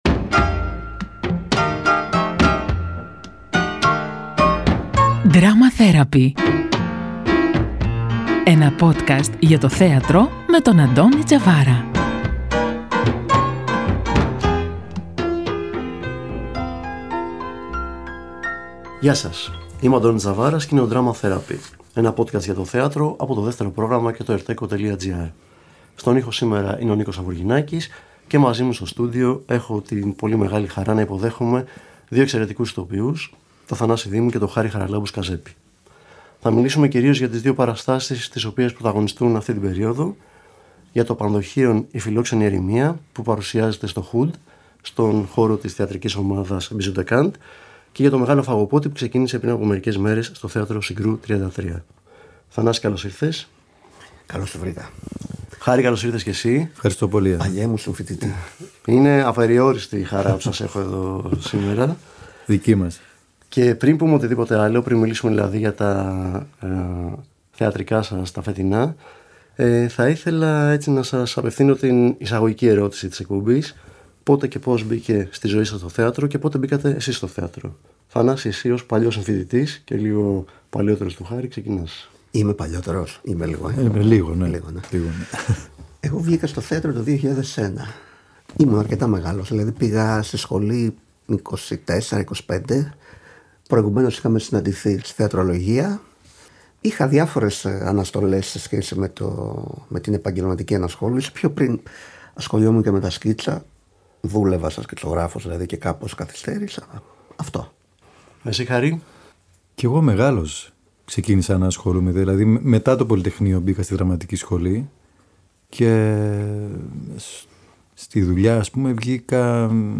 Ένα podcast για το θέατρο από το Δεύτερο Πρόγραμμα και το ERT εcho Στο Drama Therapy άνθρωποι του θεάτρου, κριτικοί και θεατές συζητούν για τις παραστάσεις της σεζόν αλλά και για οτιδήποτε μπορεί να έχει ως αφετηρία ή ως προορισμό τη θεατρική πράξη.